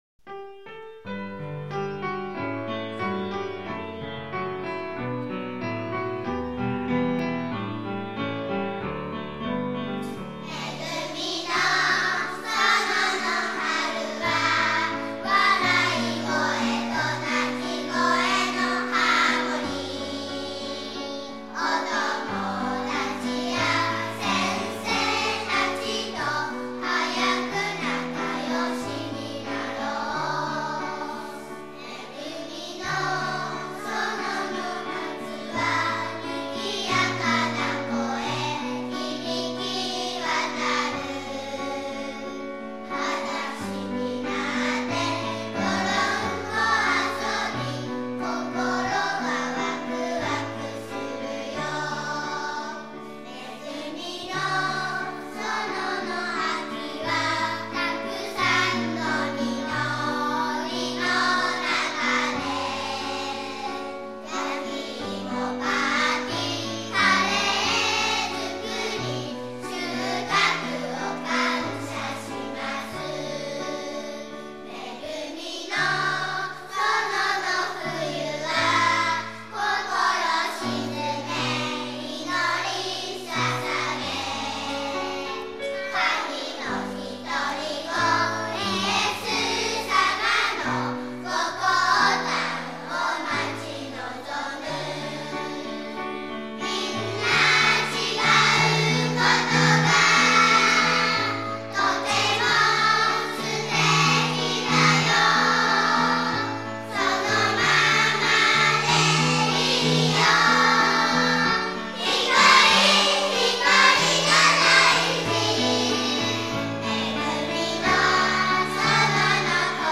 園歌「めぐみの園」
（作詞・作曲　福原由紀）